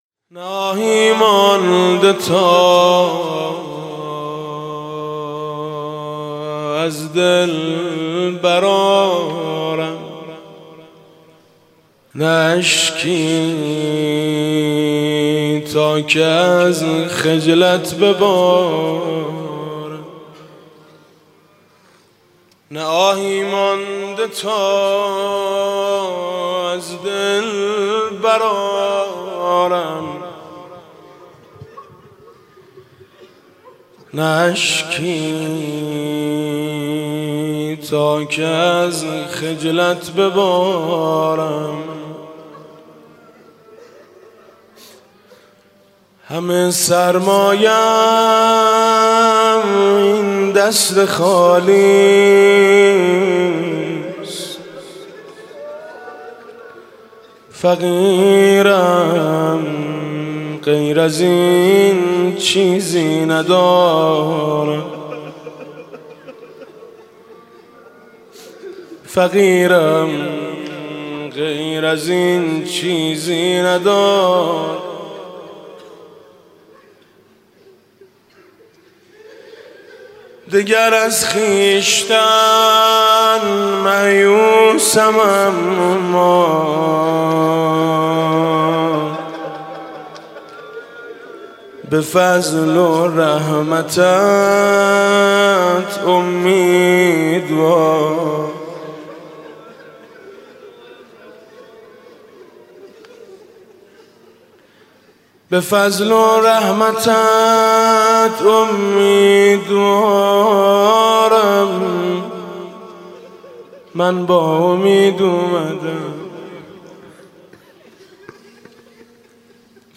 شب اول رمضان 96 - هیئت شهدای گمنام - مناجات با خدا